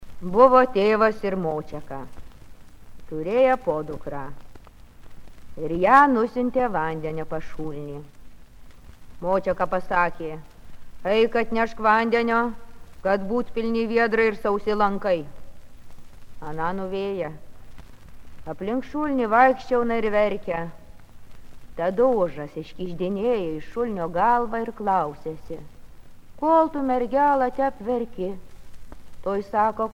Catégorie Récit